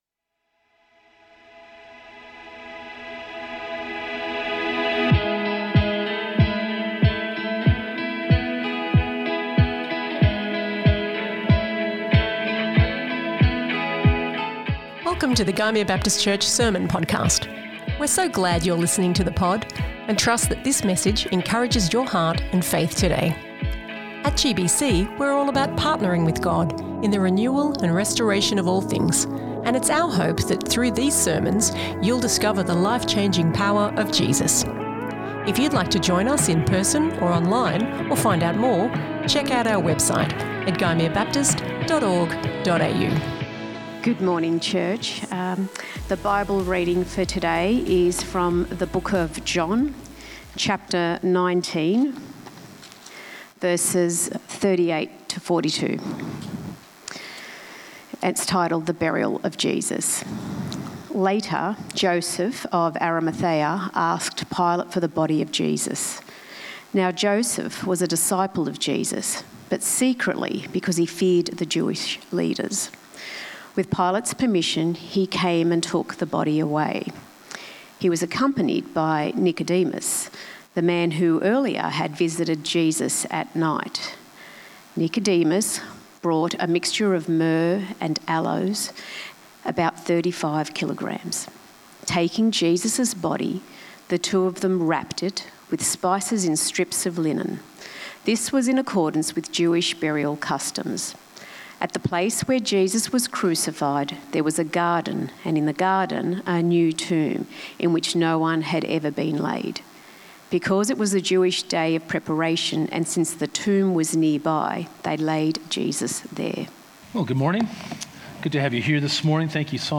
GBC | Sermons | Gymea Baptist Church
Good Friday